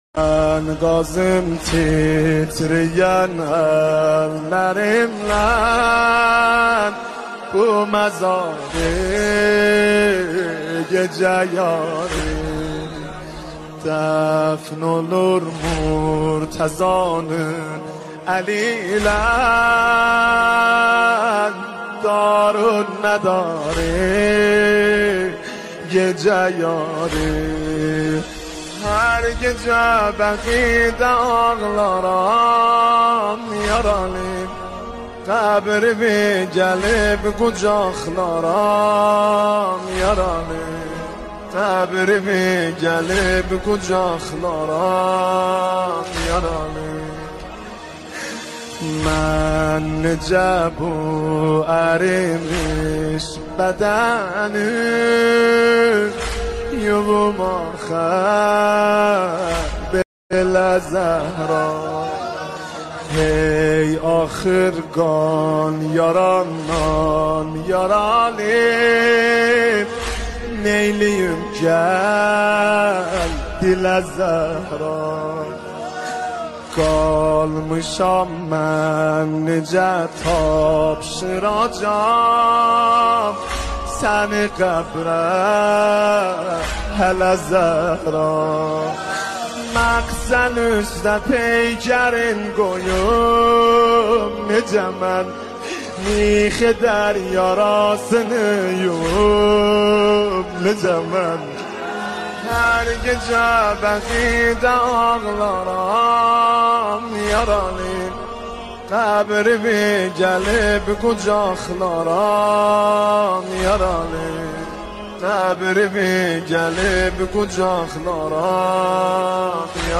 سنگین واحد سینه زنی روضه سوزناک
مداحی ترکی حضرت زهرا